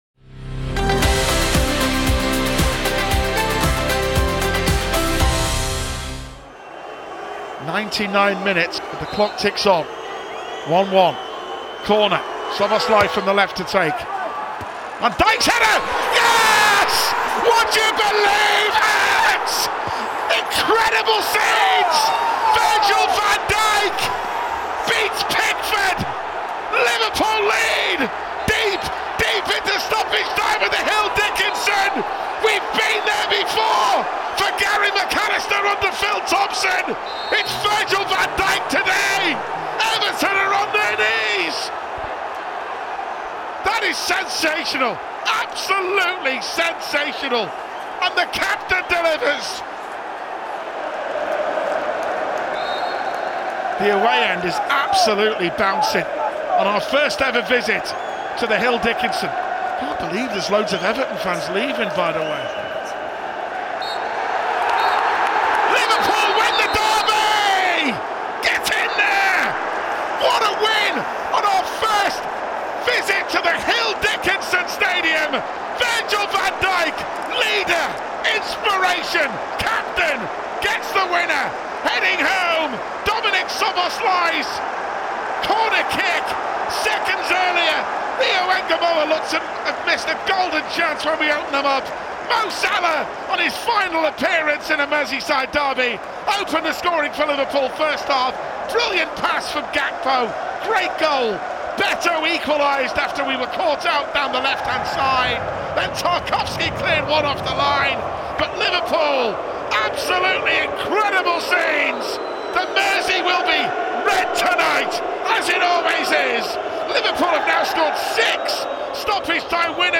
Podcast Football Daily PL Review: City take control, Spurs destined for the drop?